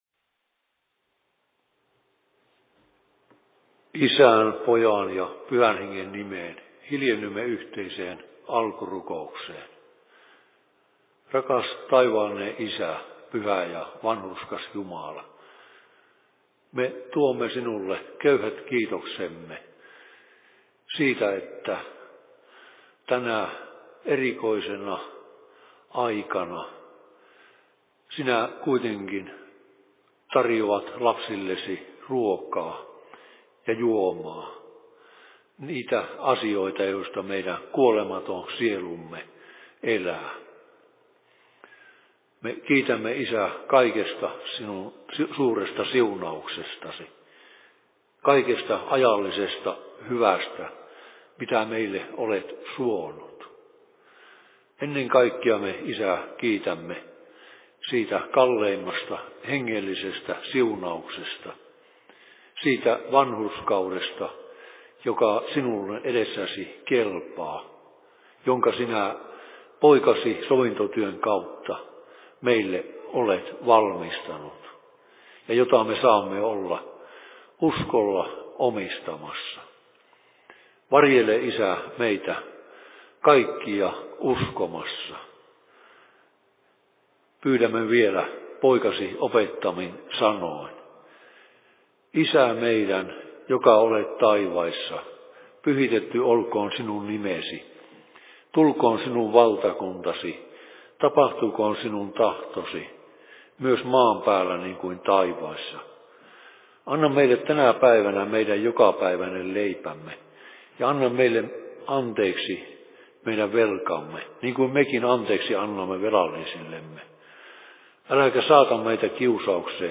Nettiseurat/Seurapuhe Kokkolan RY:llä 12.05.2020 18.30
Paikka: Rauhanyhdistys Kokkola